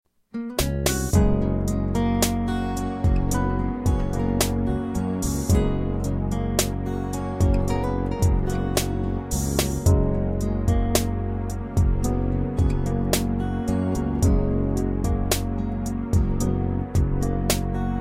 Listen to a sample of the instrumental version.